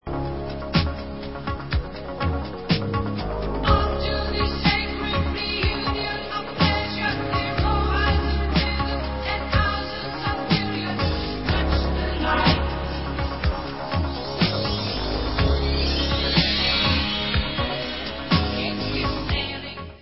Pop/Symphonic